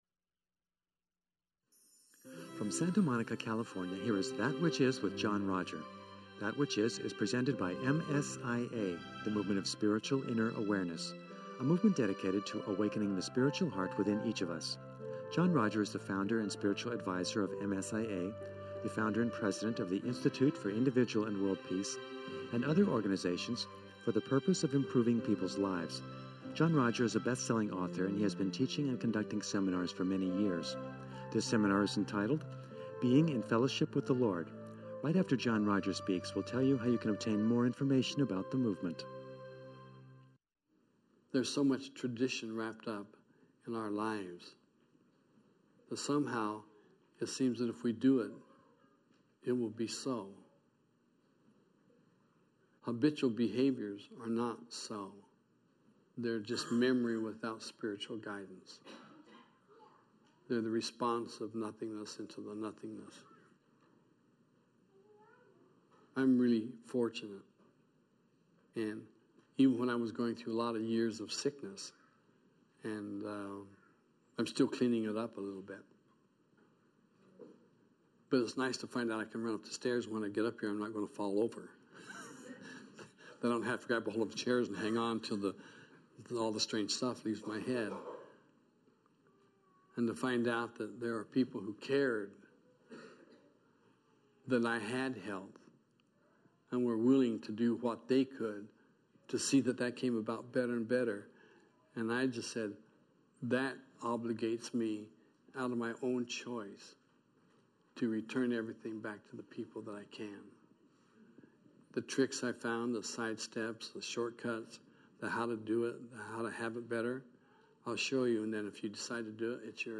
We know he can be very funny and his humor moves the audience to a place of upliftment.